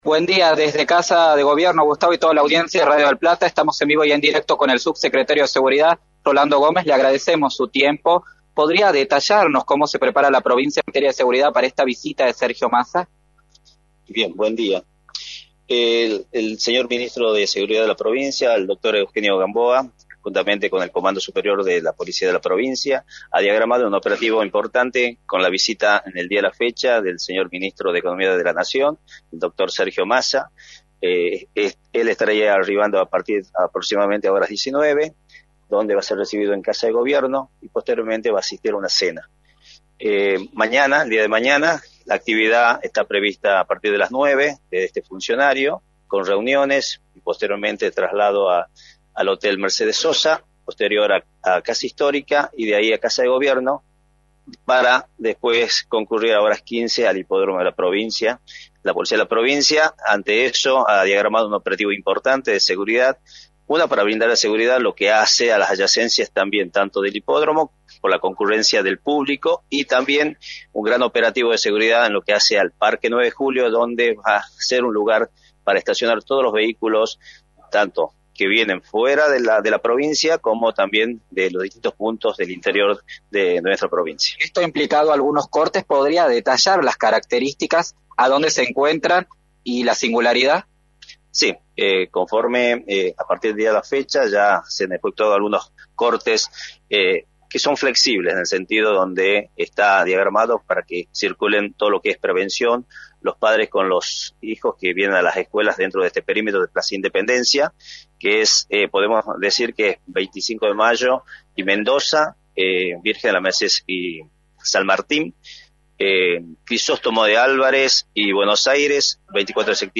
Rolando Gómez, Subsecretario de Seguridad, informó en Radio del Plata Tucumán, por la 93.9, como se prepara la provincia para la visita del Ministro de Economía y candidato a Presidente por Unión por la Patria, Sergio Massa